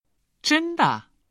（ジェン　ダ）
上がり調子で発音します。